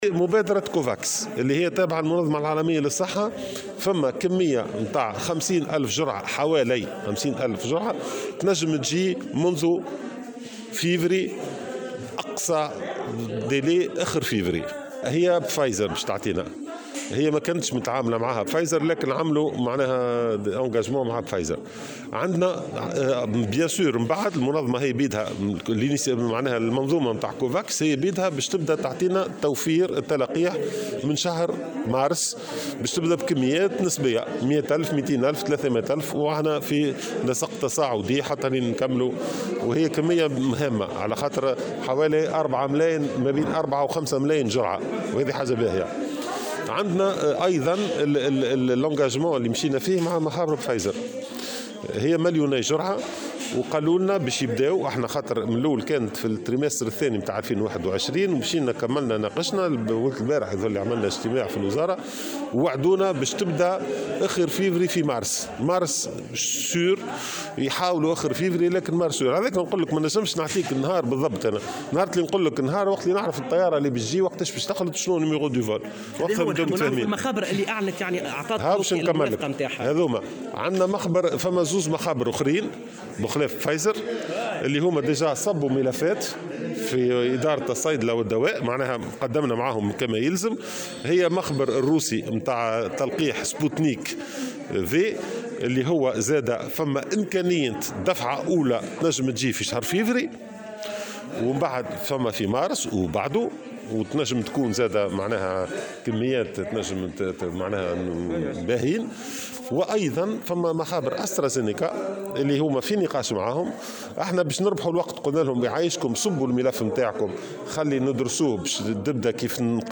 وأضاف في تصريح لمراسل "الجوهرة أف ام" على هامش ندوة صحفية عقدتها وزارة الصحة اليوم، أنه تم أيضا توقيع اتفاق مع مخبر "فايزر" للحصول على مليوني جرعة (بداية من شهر مارس القادم)، إضافة إلى تقدّم المشاورات مع المخبر الروسي "سبوتنيك" (إمكانية وصول دفعة أولى في شهر فيفري) ومخابر "استرازينيكا" ومخبر صيني آخر.